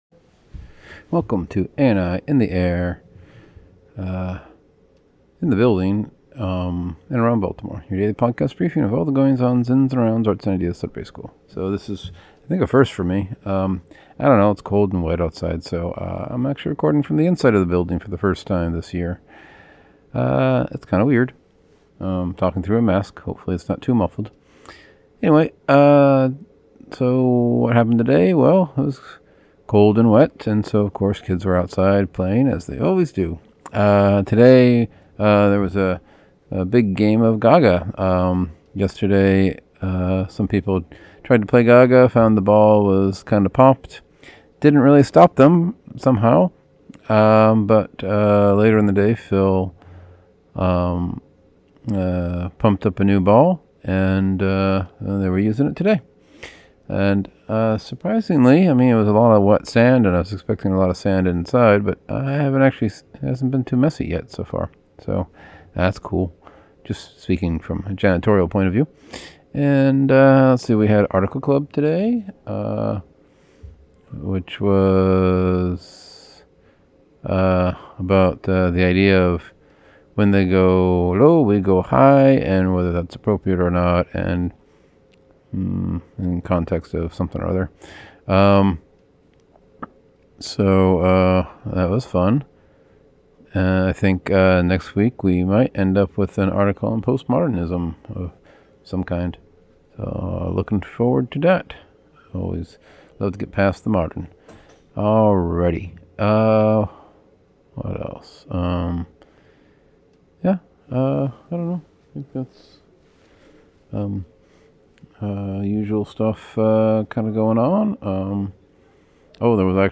Cold wet day - first indoor recording of year (through mask).